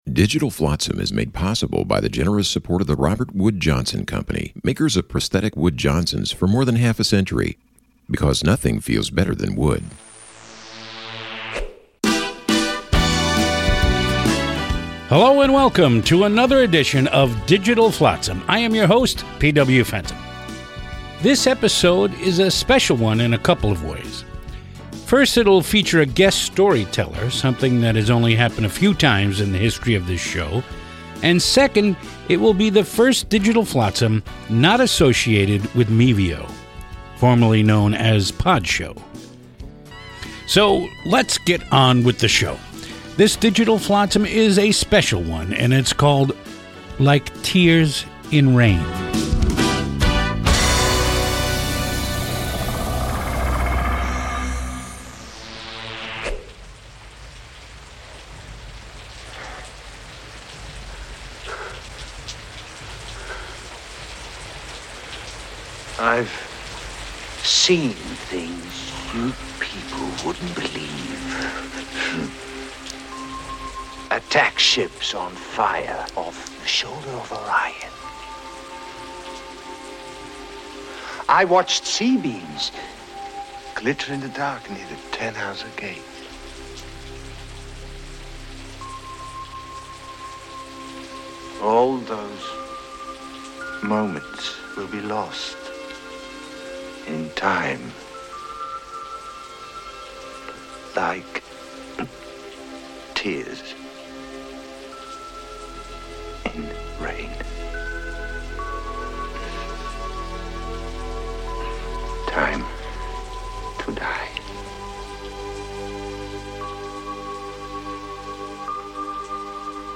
It appeared for several years nationally on Sirius Satellite Radio. We are proud to offer these great spoken word pieces again.